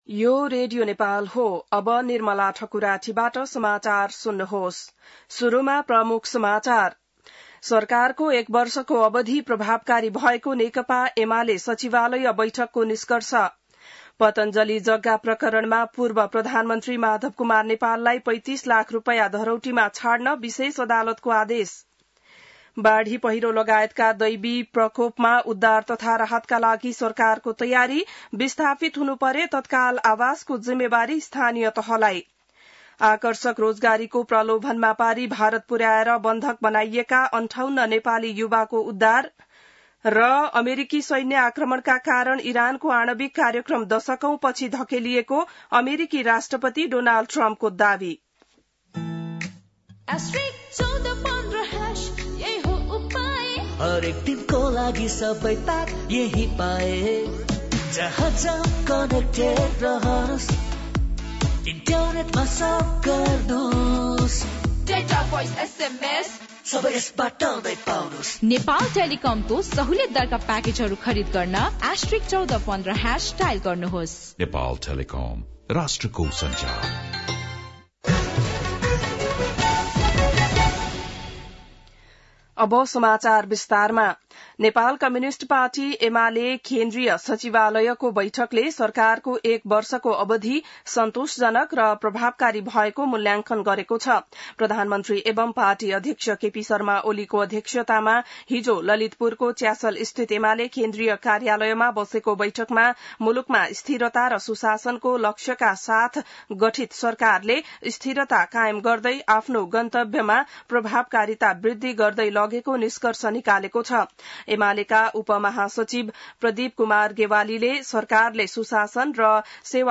बिहान ७ बजेको नेपाली समाचार : १२ असार , २०८२